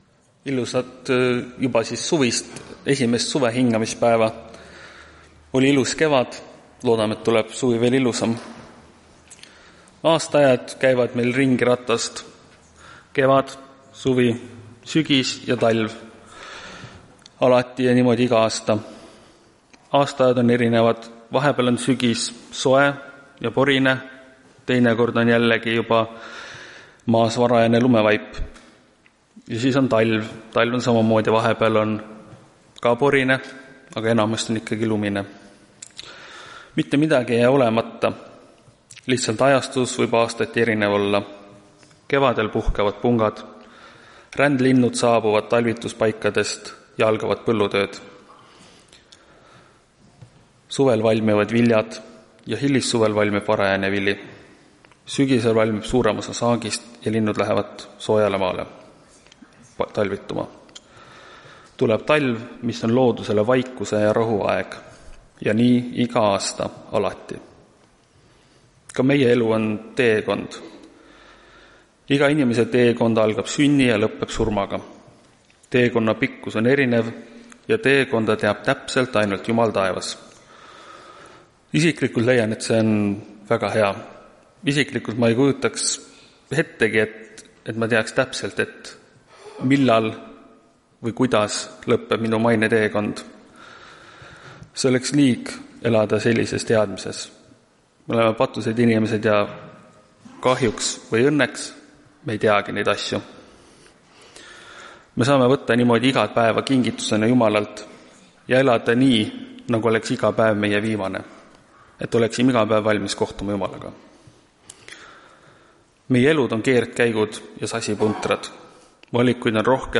Tartu adventkoguduse 22.06 hommikuse teenistuse jutluse helisalvestis.